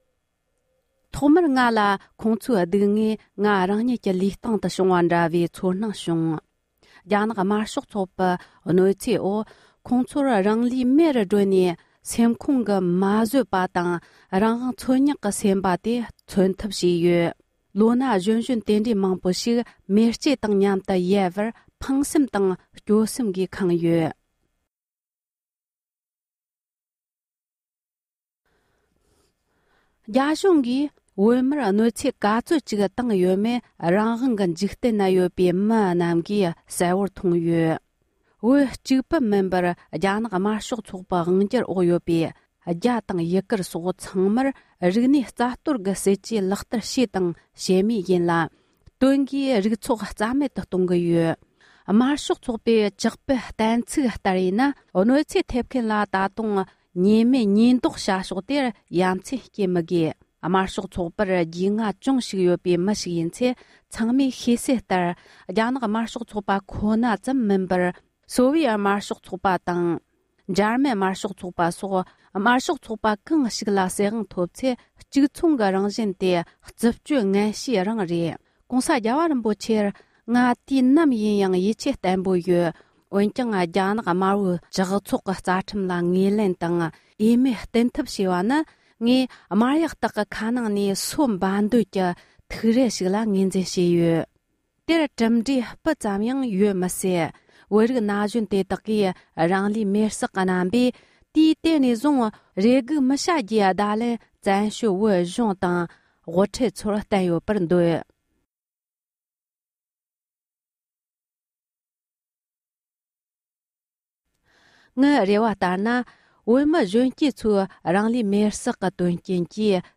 བོད་ནང་དུ་རང་ལུས་མེ་སྲེག་གི་དོན་རྐྱེན་བསྟུད་མར་བྱུང་བའི་གནས་ཚུལ་ཐད་རྒྱ་རིགས་མཁས་མ་ར་བཅར་འདྲི།